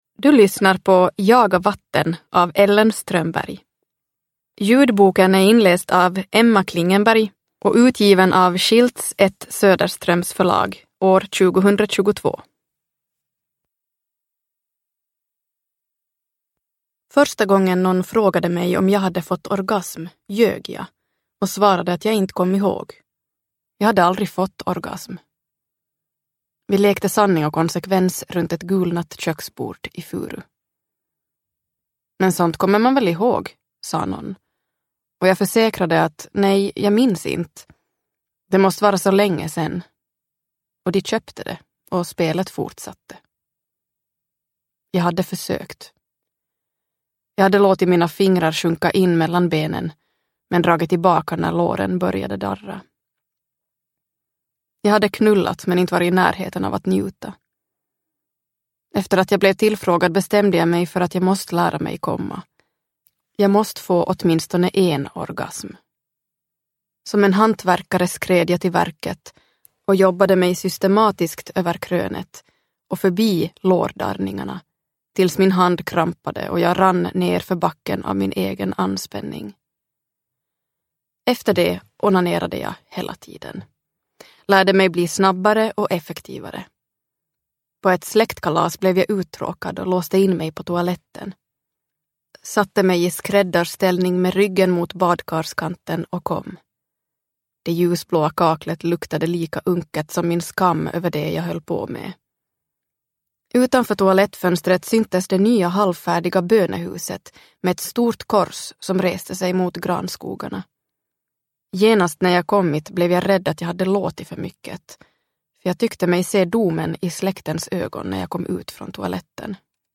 Jaga vatten – Ljudbok – Laddas ner